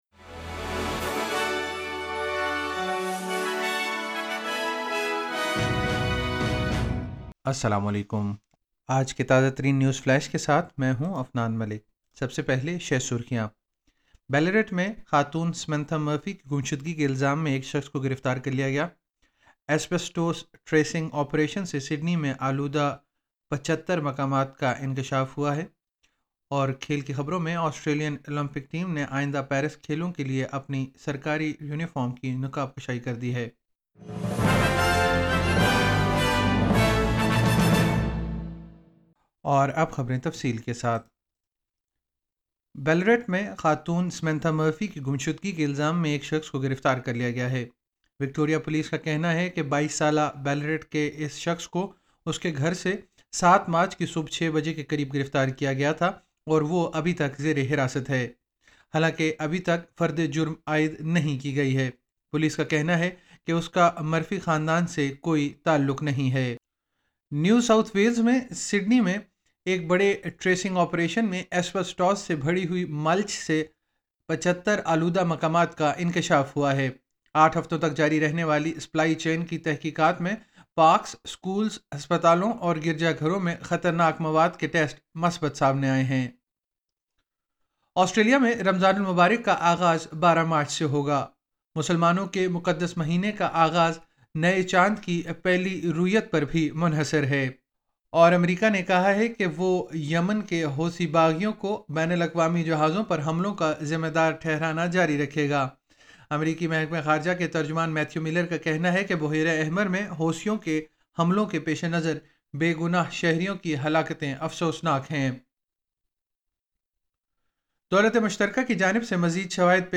نیوز فلیش 07 مارچ 2024